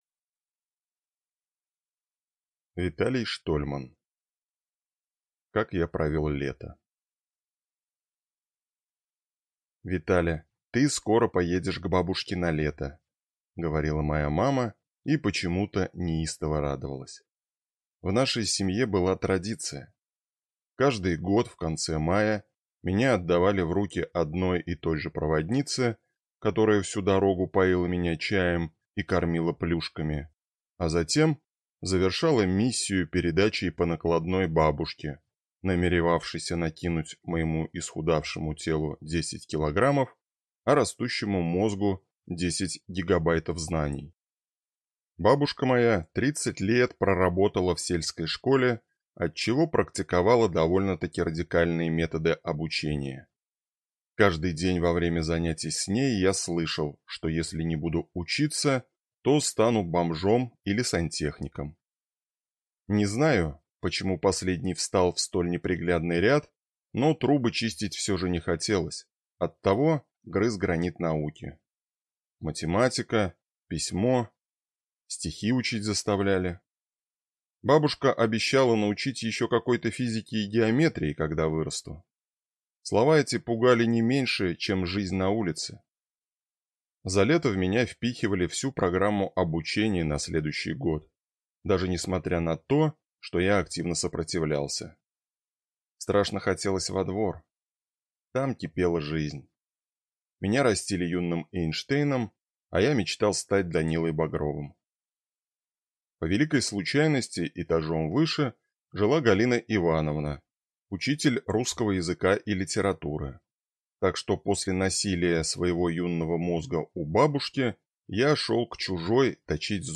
Аудиокнига Как я провел лето | Библиотека аудиокниг